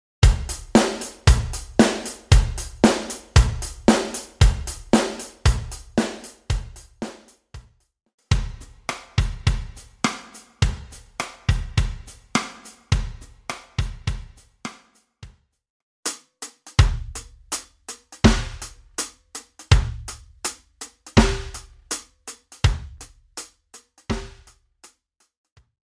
Rätsel-Track 2 – Drumbeats